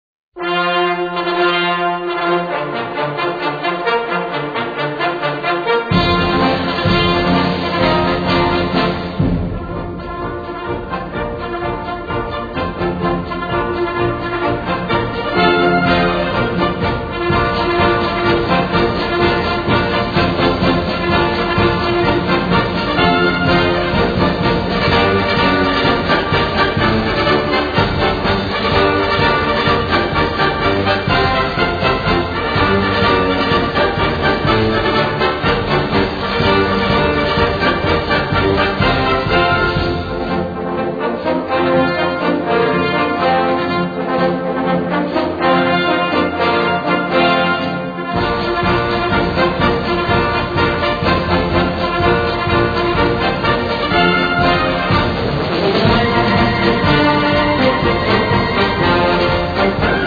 Gattung: Marsch
Besetzung: Blasorchester
im Marschtempo einen aufreizenden Effekt